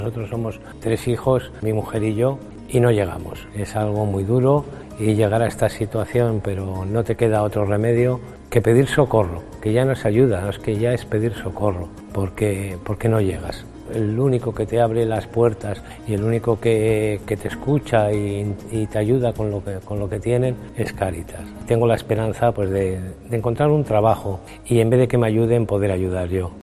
Los testimonios reales de usuarios de Cáritas La Rioja contando cómo les ha ayudado la entidad